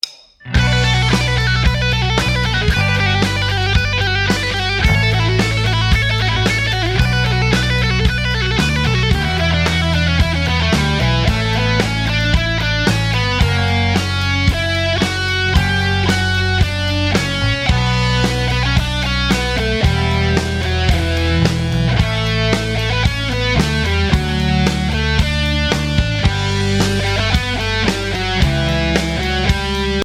Rock Lead Guitar Solos
Voicing: Guitar Method